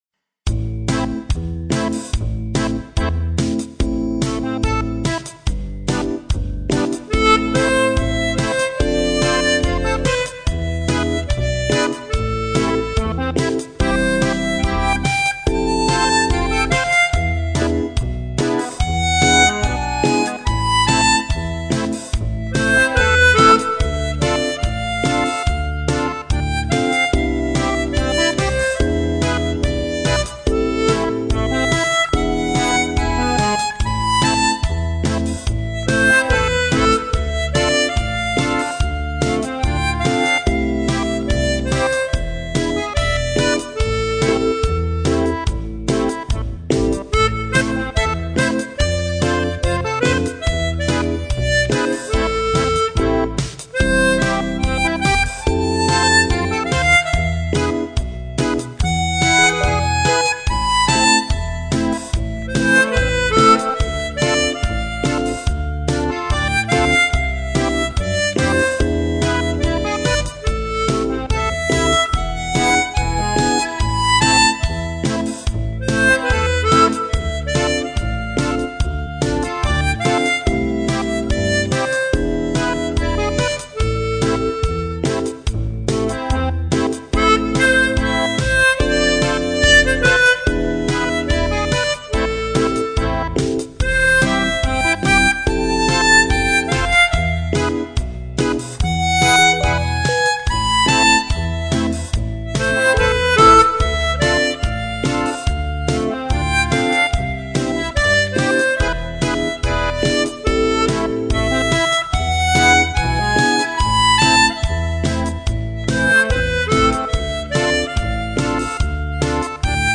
Interprétation pétillante
Très jolie mélodie.